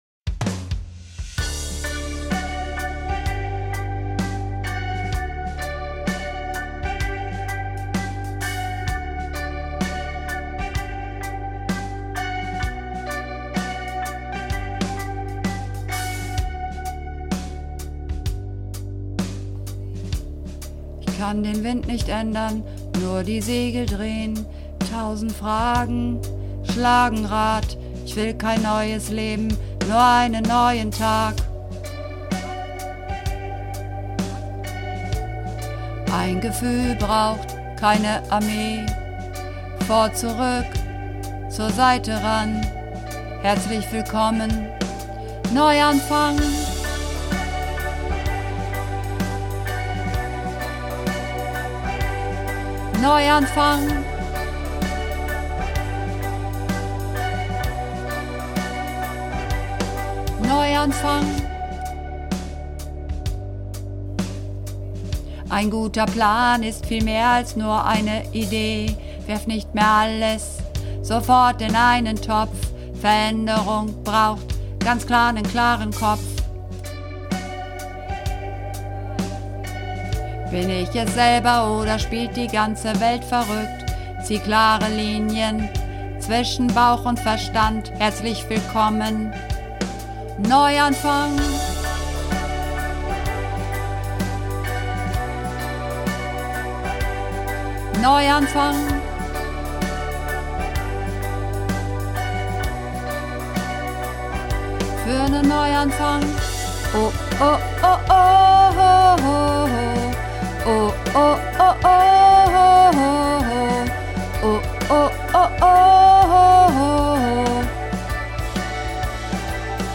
Übungsaufnahmen - Neuanfang